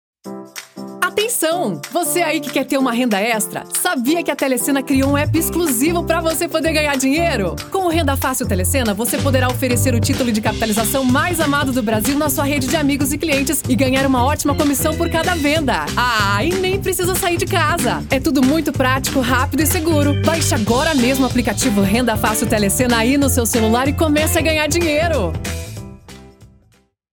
Varejo: